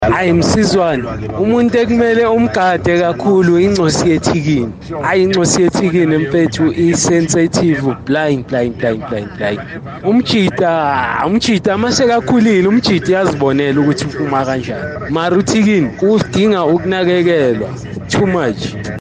Kaya Drive listeners shared their experience: